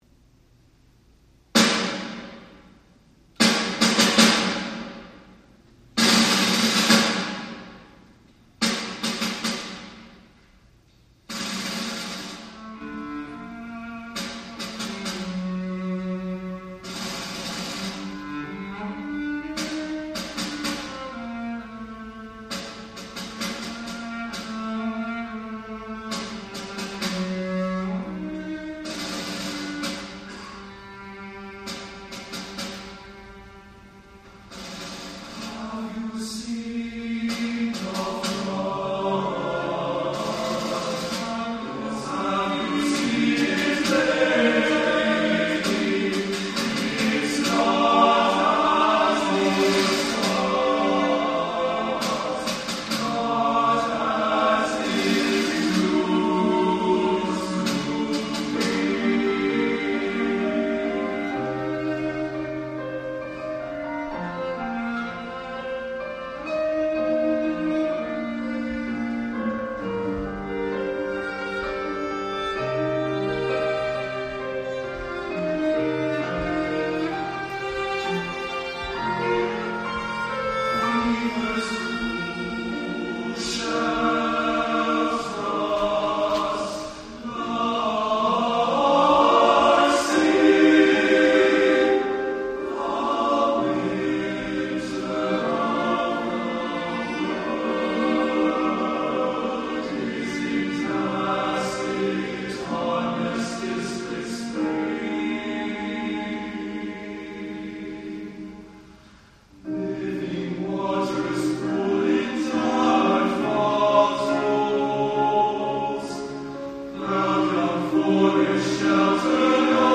Voicing: TTB/TBB and Piano